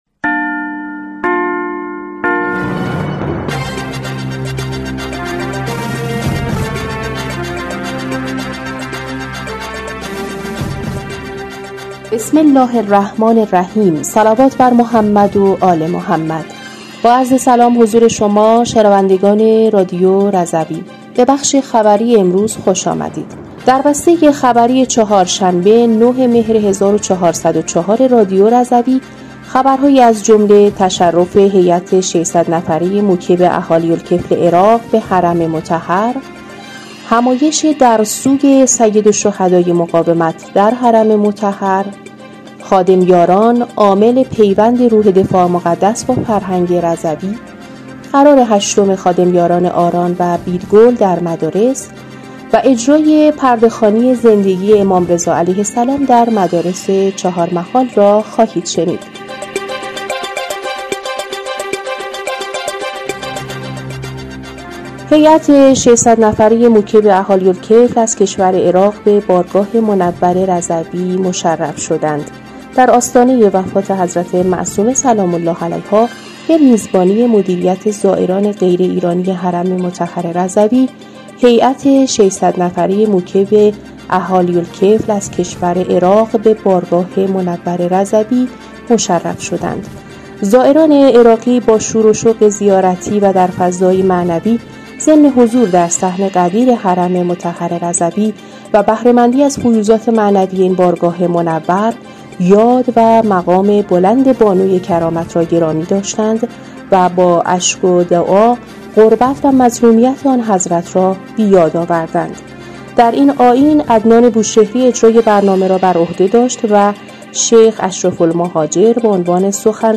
بسته خبری ۹ مهر ۱۴۰۴ رادیو رضوی؛